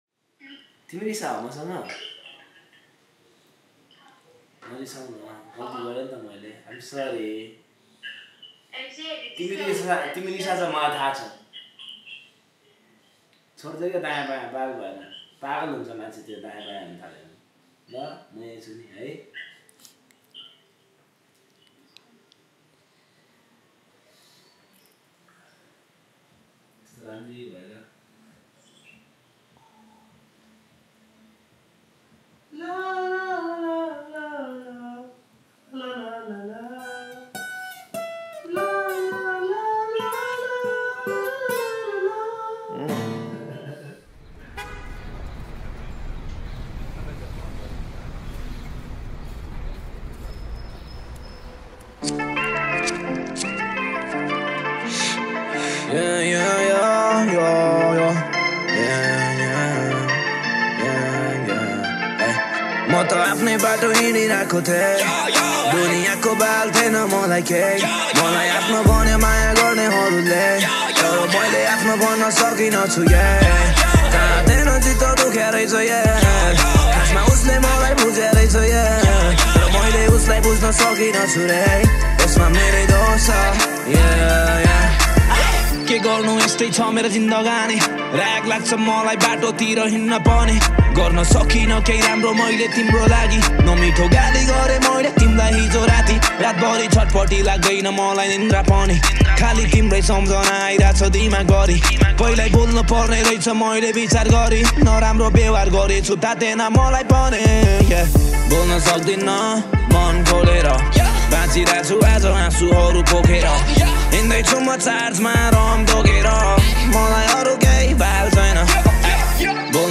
# Nepali Rap Mp3 Songs Download